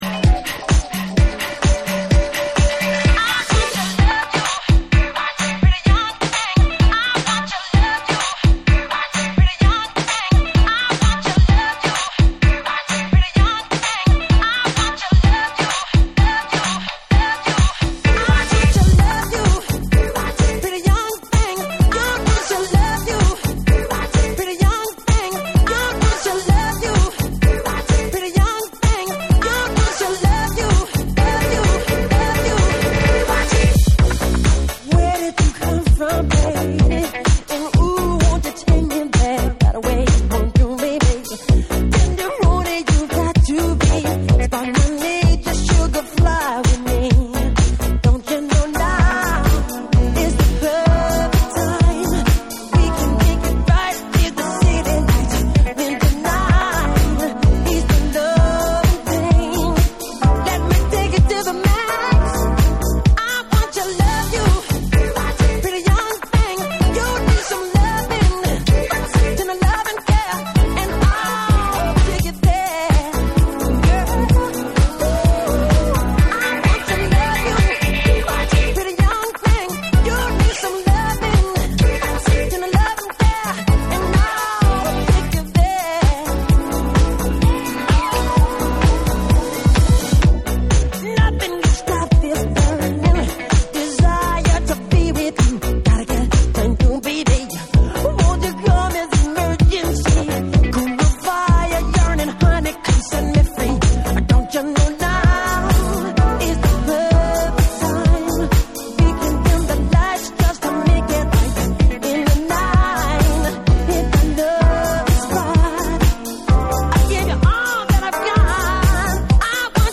リズム強化 & 長尺化でDJユースに仕立てた
TECHNO & HOUSE / RE-EDIT / MASH UP